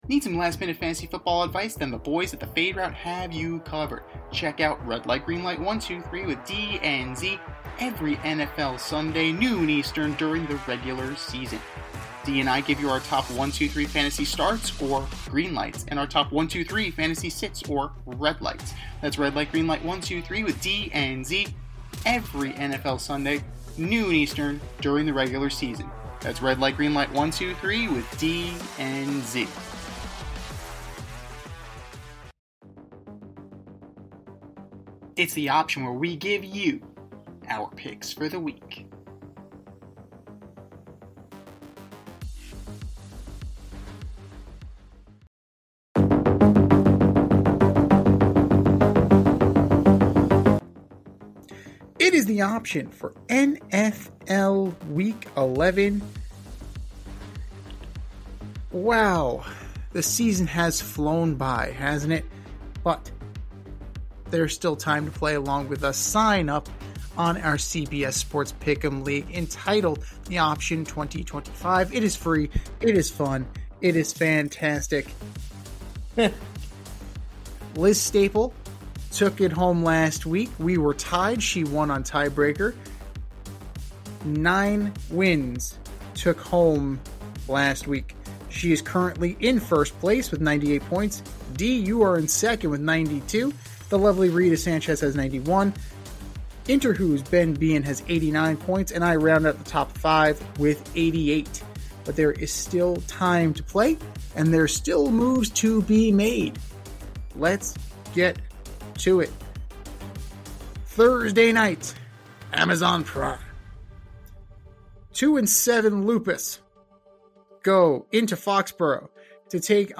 two veteran sports aficionados and lifelong friends
with wit and a touch of New York flair